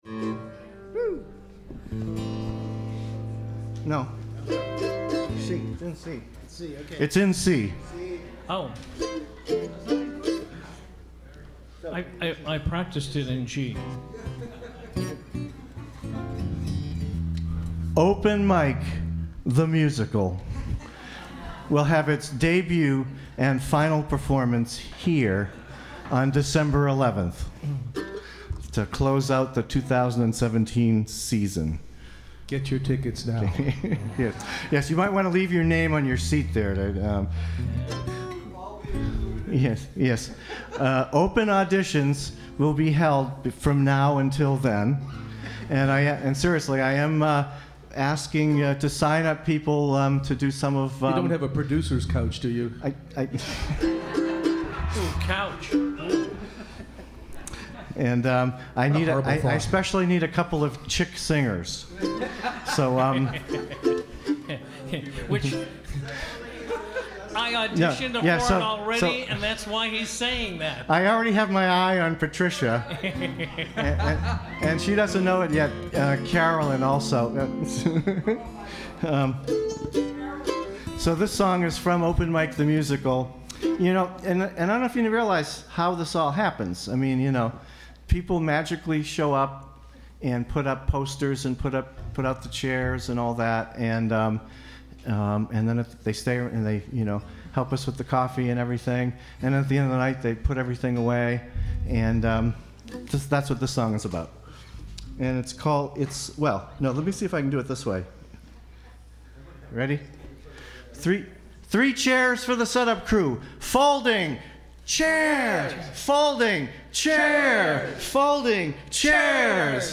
4_Three Chairs - at ROM Nov16.mp3